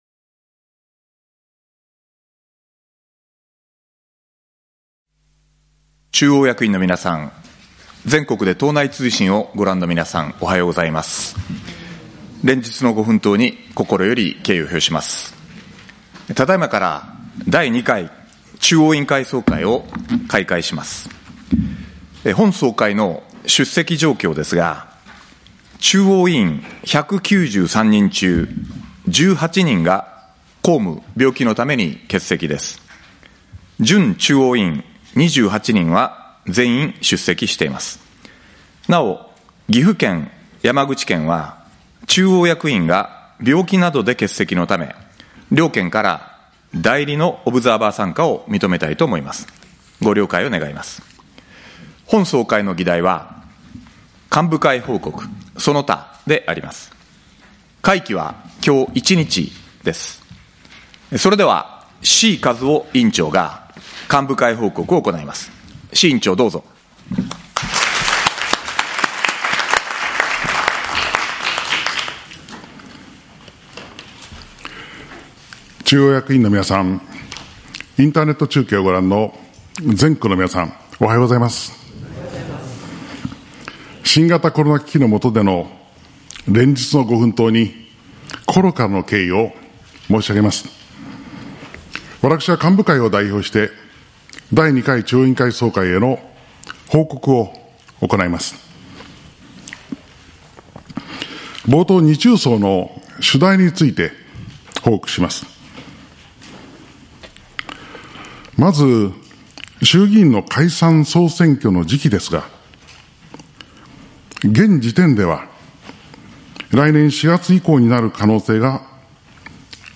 第２回中央委員会総会（第28回党大会）
志位和夫委員長の幹部会報告
志位委員長の報告の音声だけ